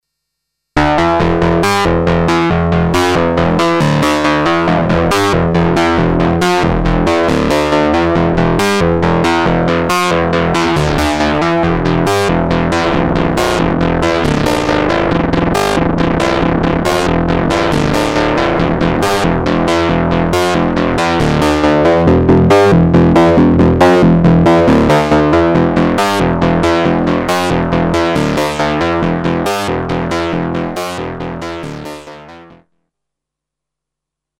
RingSync - sync-sound with 2 RingMods
Tweaking VCO2, VCO3, Audio-In and Emphasis, using other sequencer to show velocity.